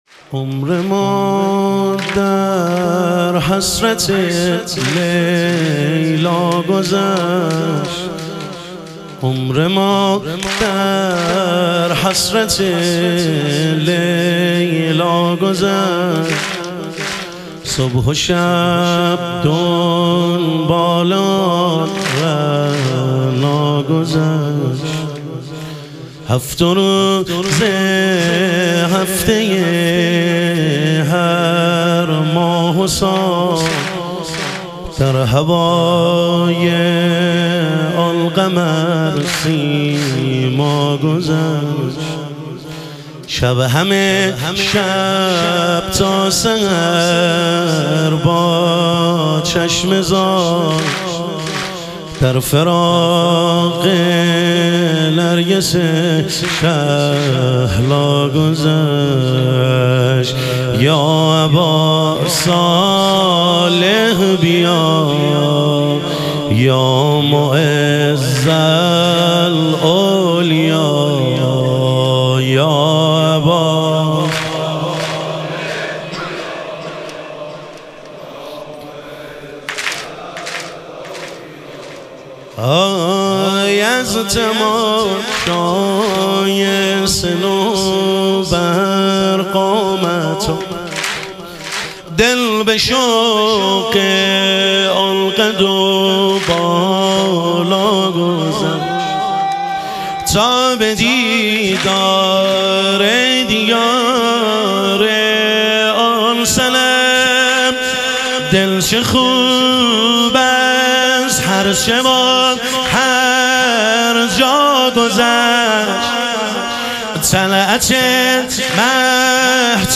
اربعین امیرالمومنین علیه السلام - واحد